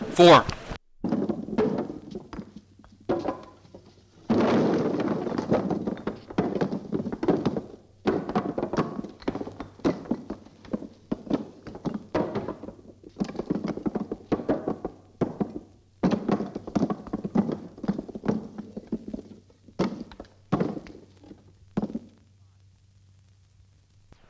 古老的碰撞 " G2604砖头落地
描述：砖落和打木头。垩白砰砰声和咔嗒声。 这些是20世纪30年代和20世纪30年代原始硝酸盐光学好莱坞声音效果的高质量副本。 40年代，在20世纪70年代早期转移到全轨磁带。我已将它们数字化以便保存，但它们尚未恢复并且有一些噪音。